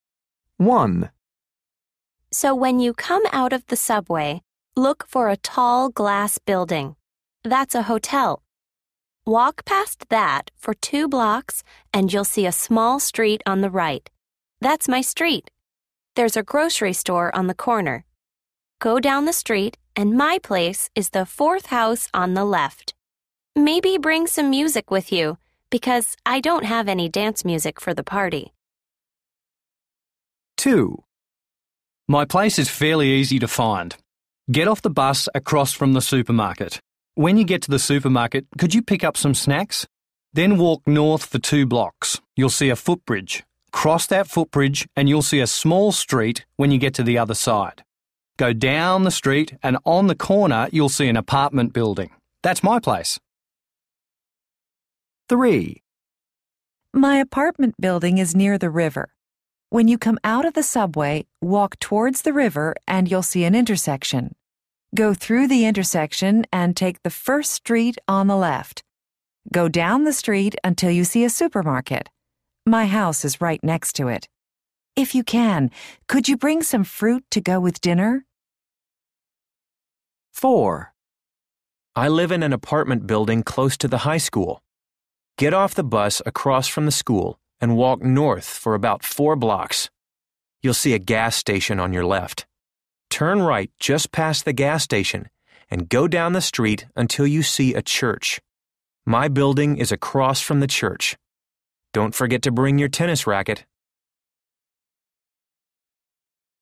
A. People are giving directions to their homes.